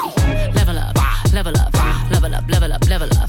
Tags: Epic